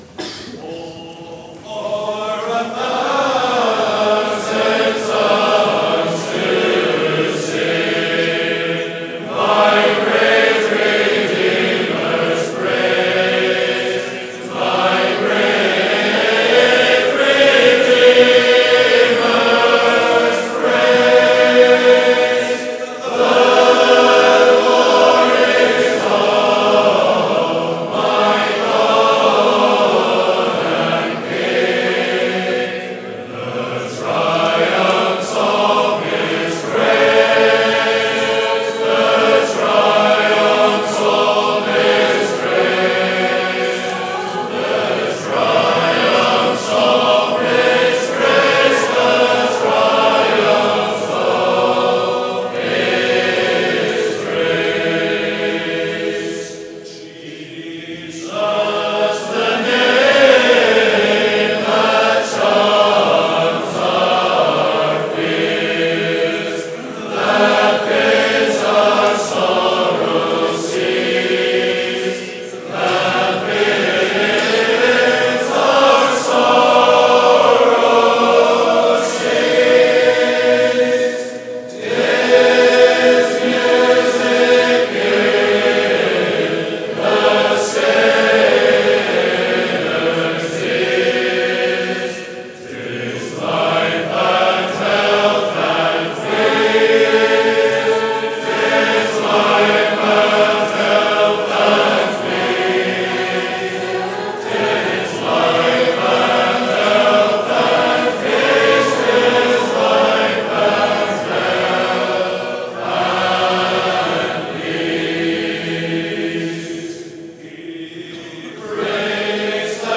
Hymns from the Saturday and Lord’s Day meetings of the 2026 Easter Conference:
2026-Easter-Hymn-Singing-Part-1.mp3